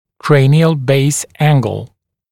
[‘kreɪnɪəl beɪs ‘æŋgl][‘крэйниэл бэйс ‘энгл]угол основания черепа